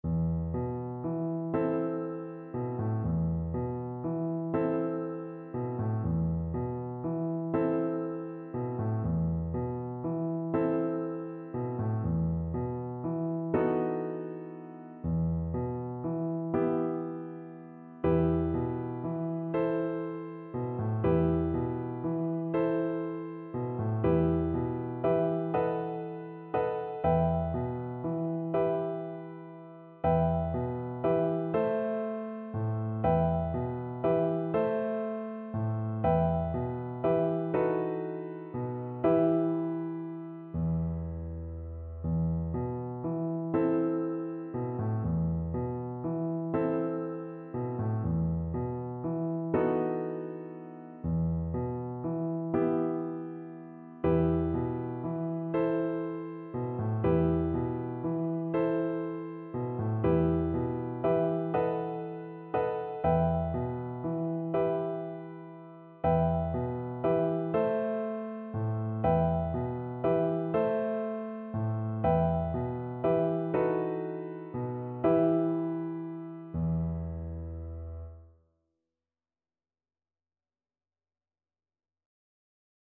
Play (or use space bar on your keyboard) Pause Music Playalong - Piano Accompaniment Playalong Band Accompaniment not yet available transpose reset tempo print settings full screen
E minor (Sounding Pitch) (View more E minor Music for Violin )
Gently rocking .=c.40
6/8 (View more 6/8 Music)